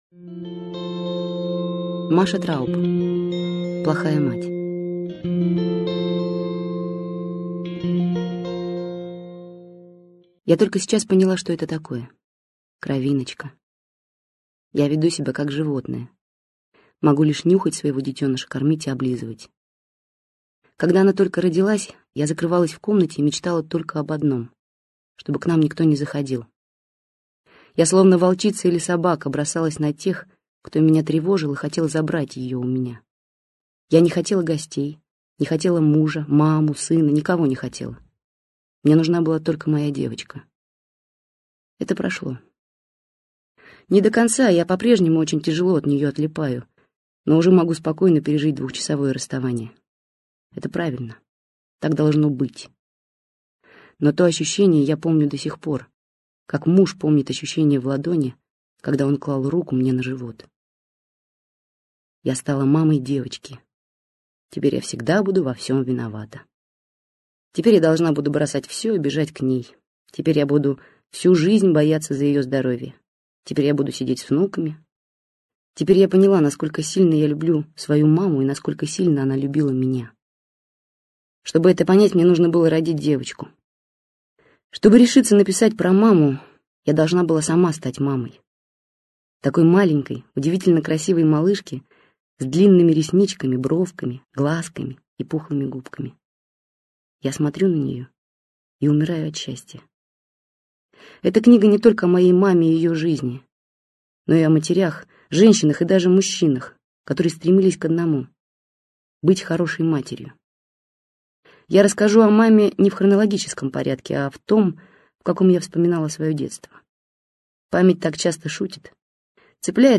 Аудиокнига Плохая мать - купить, скачать и слушать онлайн | КнигоПоиск